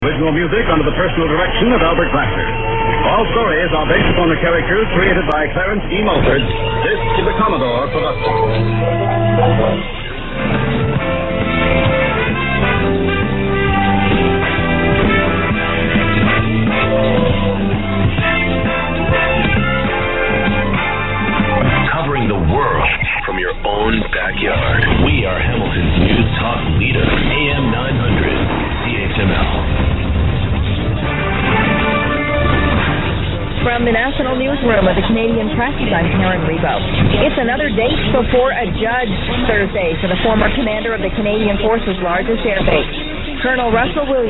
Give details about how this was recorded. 101007_0600_830_wtru_nc_lsb_then_usb.mp3